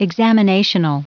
Prononciation du mot examinational en anglais (fichier audio)
Prononciation du mot : examinational
examinational.wav